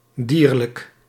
Ääntäminen
US : IPA : [ˈprɪ.mɪ.tɪv]